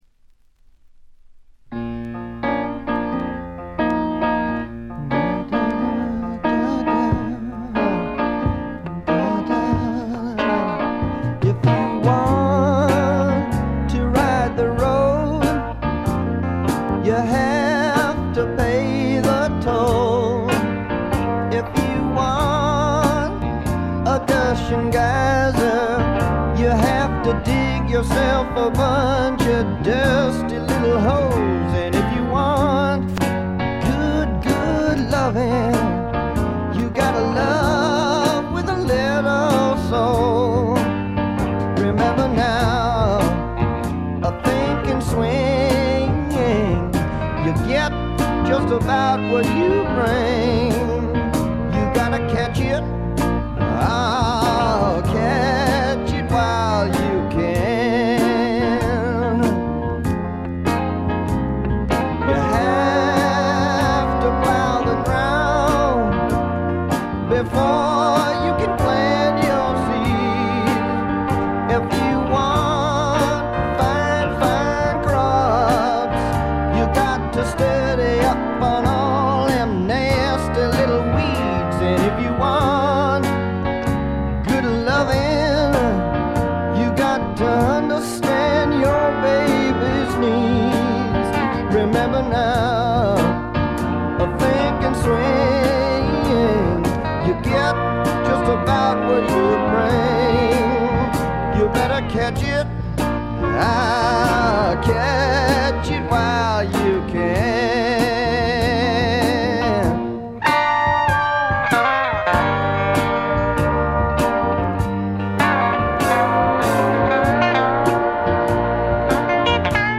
軽微なチリプチ少し。A1序盤でプツ音1回。
わざわざ言うまでもなく米国東海岸を代表するグッドタイムミュージックの超絶名盤です。
試聴曲は現品からの取り込み音源です。